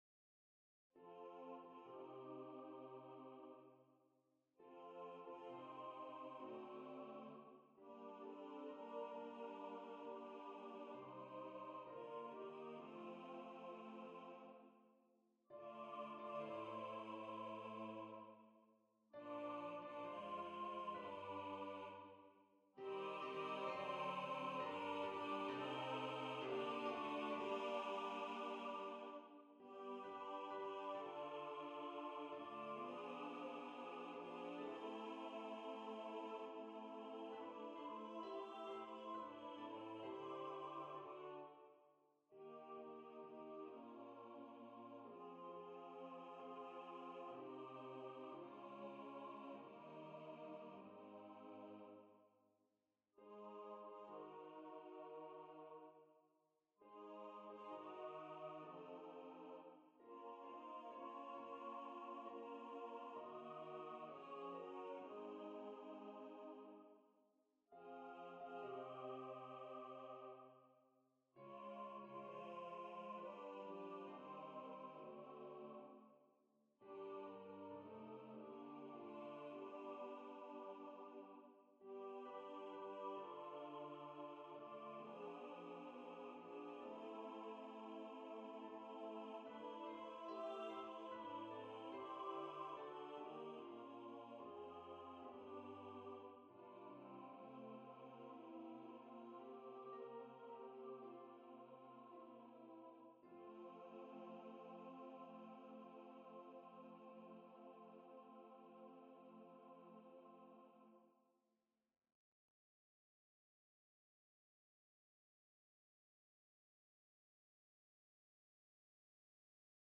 Voices: Mixed Chorus (SATB) Instrumentation: a cappella
NotePerformer 4 - mp3 Download/Play Audio